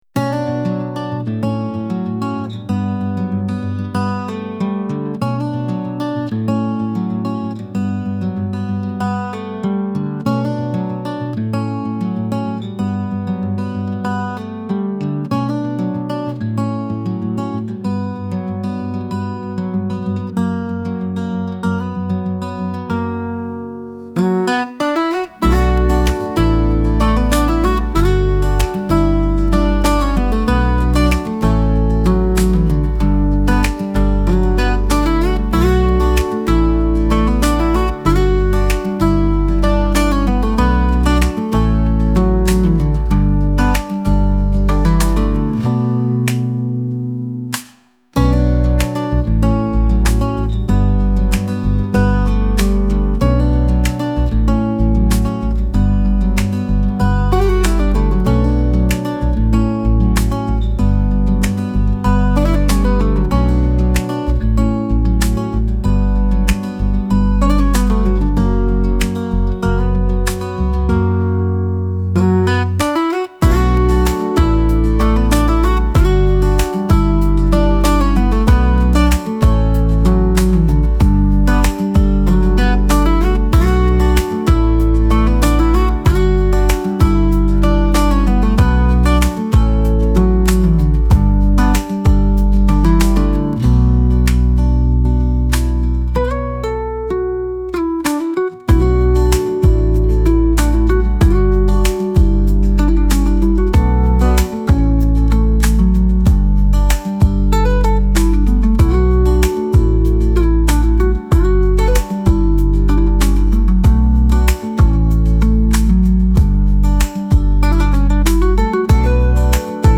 かっこいい どこか懐かしい夏 悲しい